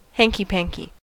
Uttal
Alternativa stavningar hanky panky Synonymer mischief jiggery-pokery Uttal US RP: IPA : /ˈhæn.kiː.ˌpæn.kiː/ Ordet hittades på dessa språk: engelska Ingen översättning hittades i den valda målspråket.